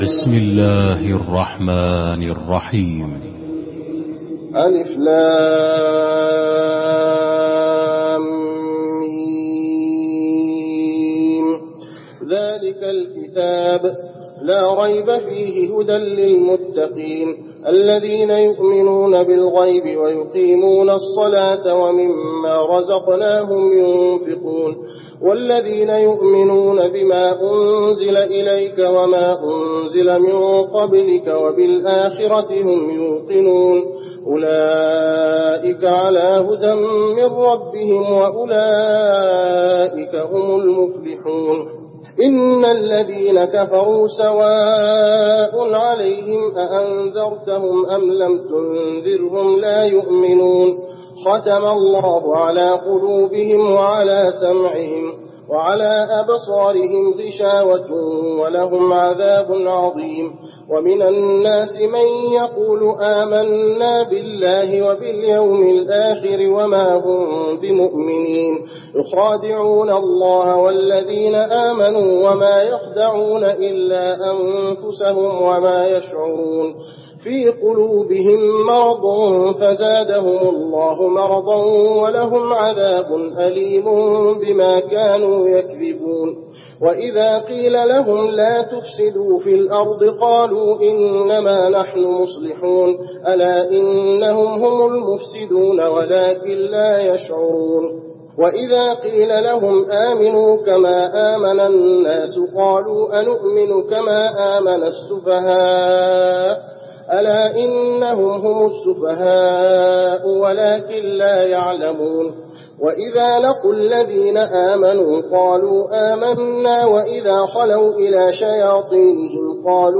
المكان: المسجد النبوي البقرة